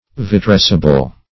Search Result for " vitrescible" : The Collaborative International Dictionary of English v.0.48: Vitrescible \Vi*tres"ci*ble\, a. [Cf. F. vitrescible.] That may be vitrified; vitrifiable.